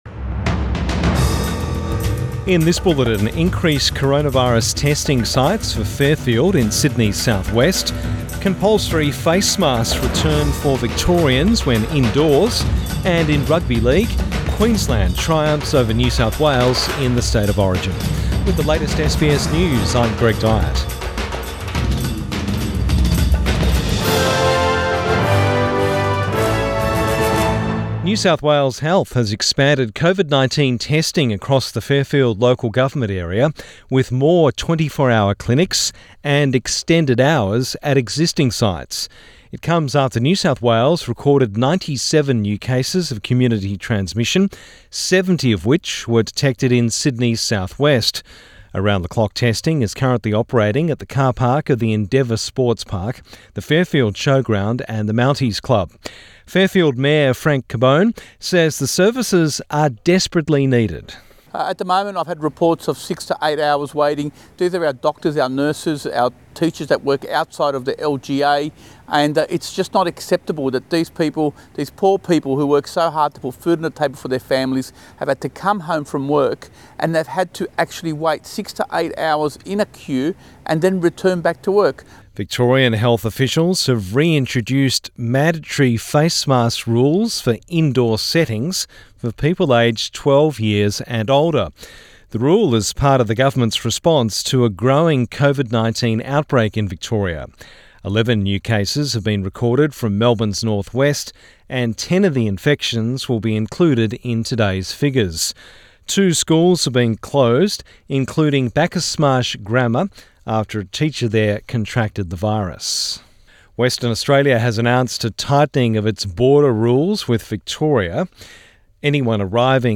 AM bulletin 15 July 2021